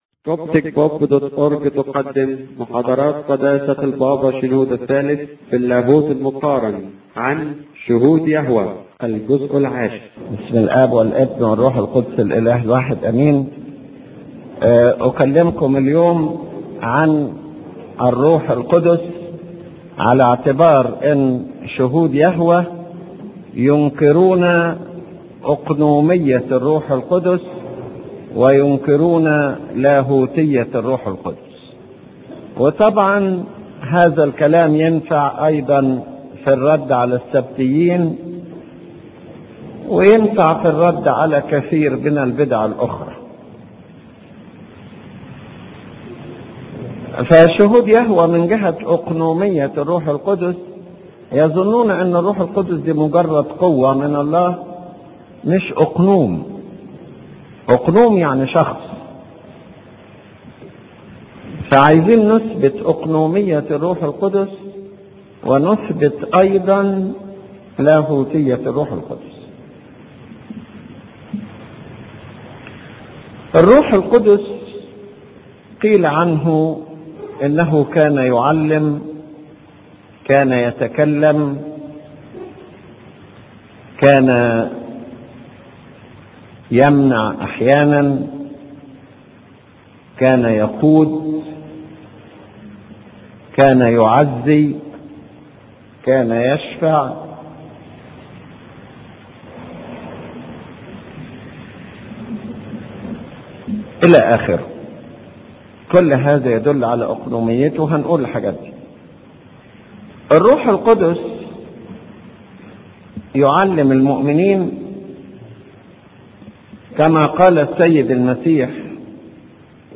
width[350];height[60];autostart[true];title[Lecture 1]